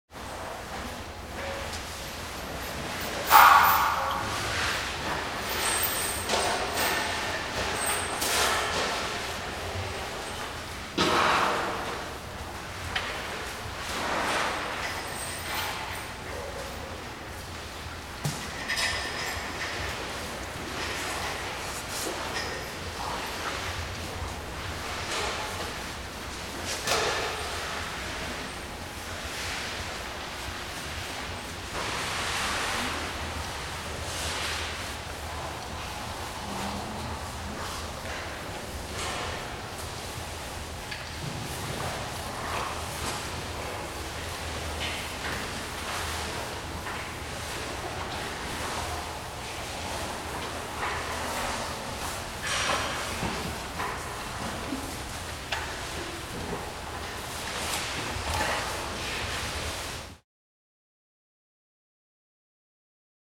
جلوه های صوتی
دانلود صدای فیل 10 از ساعد نیوز با لینک مستقیم و کیفیت بالا
برچسب: دانلود آهنگ های افکت صوتی انسان و موجودات زنده دانلود آلبوم صدای حیوانات وحشی از افکت صوتی انسان و موجودات زنده